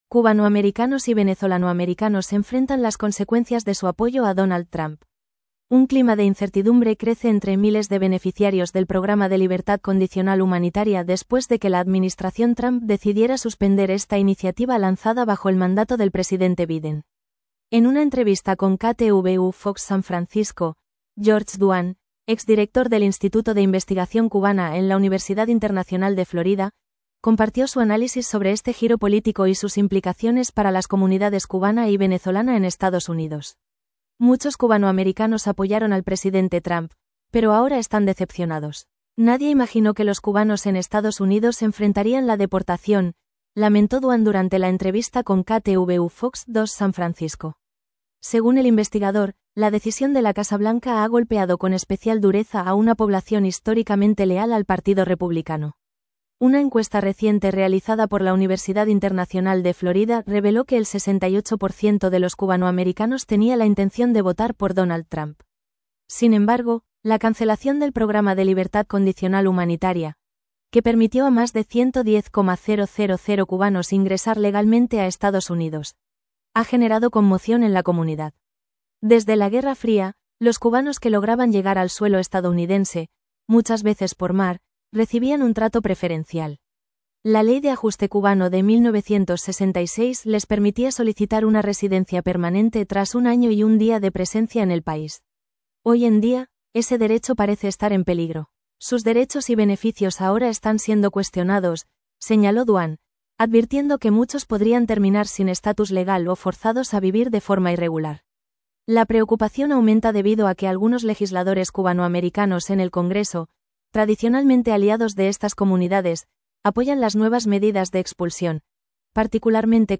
En una entrevista con KTVU Fox San Francisco